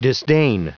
Prononciation du mot disdain en anglais (fichier audio)
Prononciation du mot : disdain